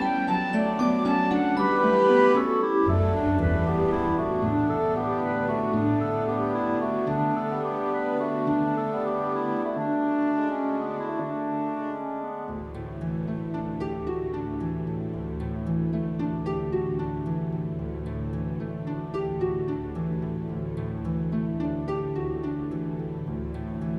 With Female Part Musicals 3:48 Buy £1.50